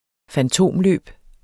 Udtale [ fanˈtoˀmˌløˀb ]